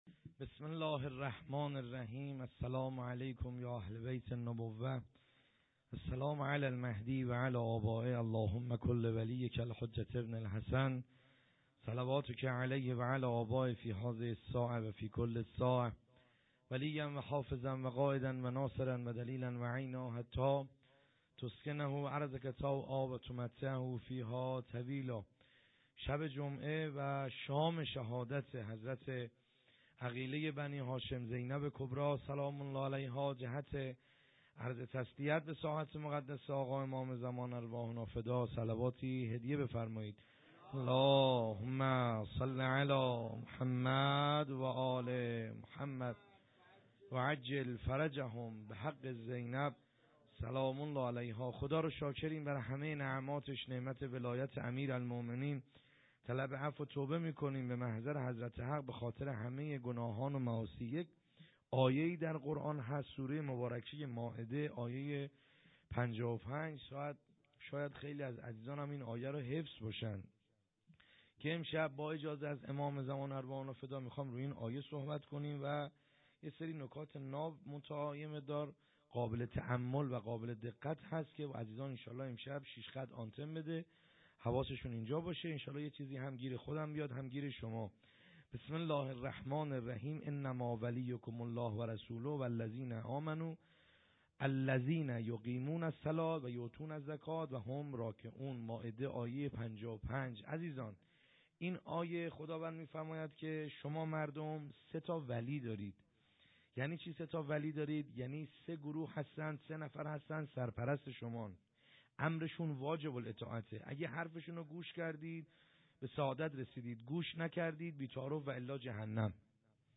خیمه گاه - بیرق معظم محبین حضرت صاحب الزمان(عج) - سخنرانی | کیفیت پایین